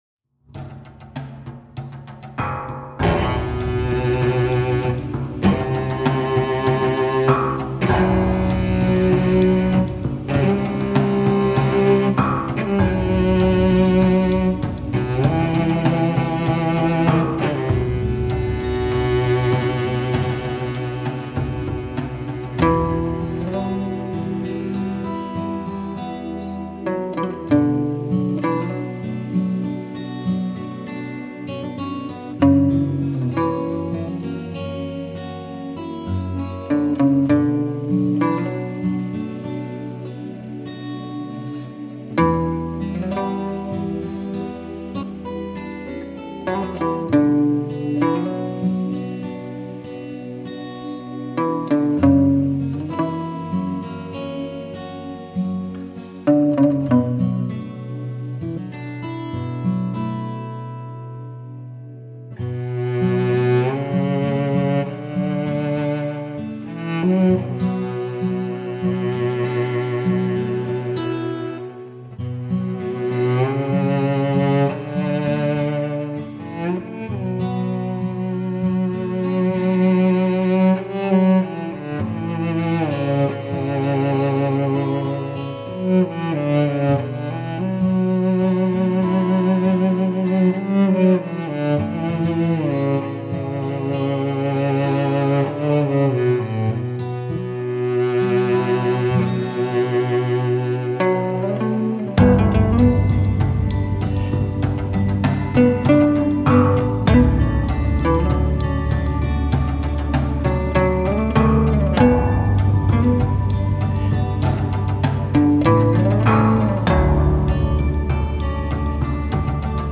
& 阮与大提琴的对话
一中一西 首張阮与大提琴器乐的絕妙合作
听到中国弹拨乐阮咸的新风格
阮&大提琴
阮的声音，感觉有点像吉他，有时候还有点像鼓，有时候又有点像大提琴...